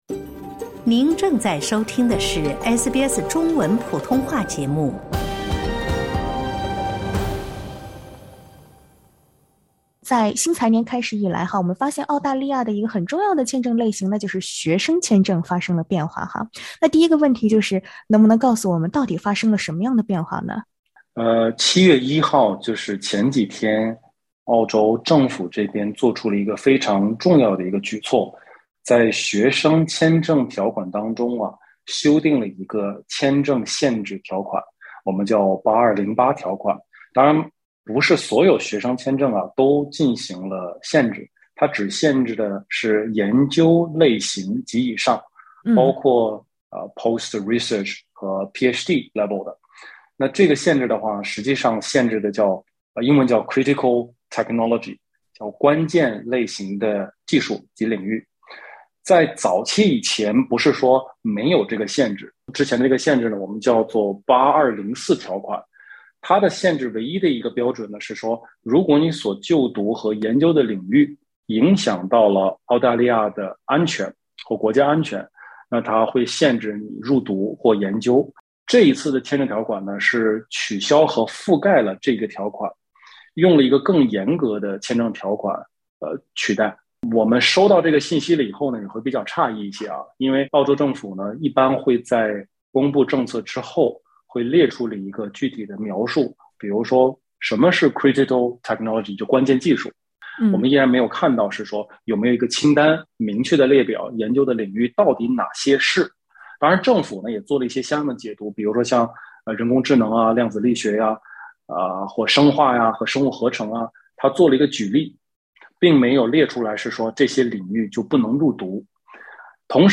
七月起，澳大利亚学生签证设置了新的限制条款，禁止涉及关键领域的研究型硕士、博士等国际学生入境就读。（点击上图收听采访）